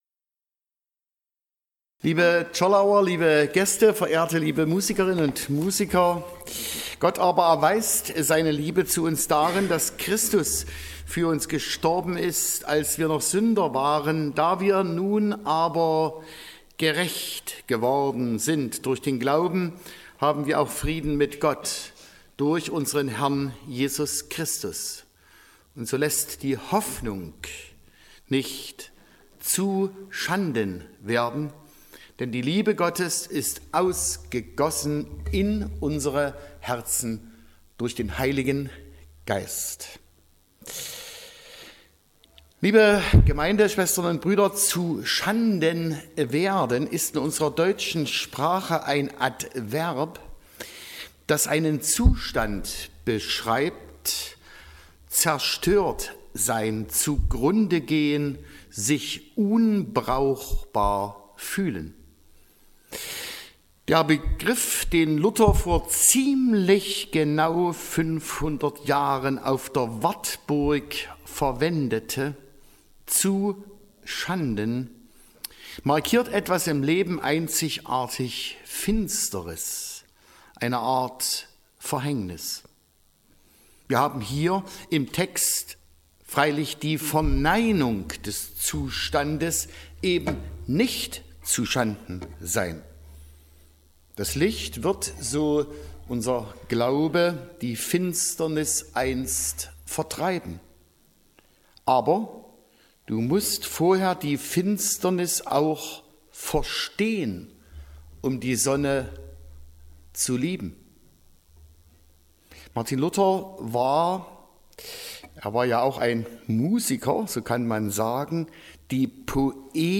Predigt vom Sonntag Reminiscere nachhören
Reminiscere_Predigt.mp3